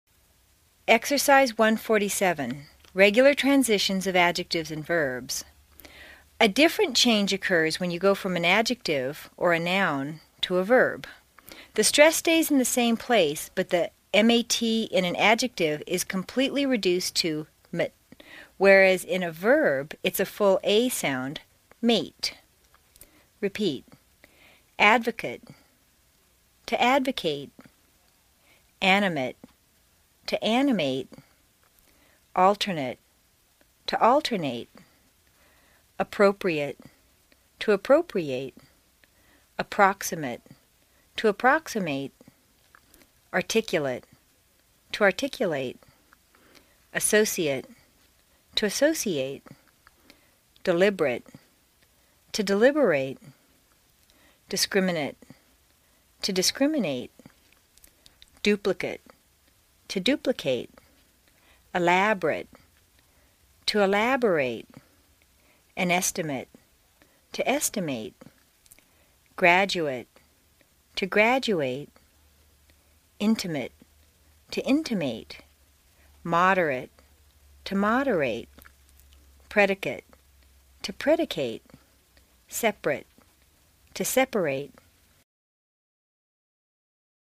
在线英语听力室美式英语正音训练第38期:Exercise 1-47 Regular Transitions of Adjectives and Verbs的听力文件下载,详细解析美式语音语调，讲解美式发音的阶梯性语调训练方法，全方位了解美式发音的技巧与方法，练就一口纯正的美式发音！